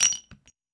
weapon_ammo_drop_04.wav